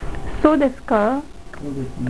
Le "soo" se prononce comme le mot "saut" et avec un son "o" allongé.